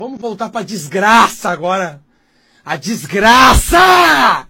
bistecone a desgrac4aaaaa Meme Sound Effect